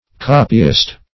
Copyist \Cop"y*ist\, n.